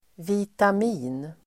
Uttal: [vitam'i:n]